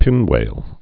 (pĭnwāl)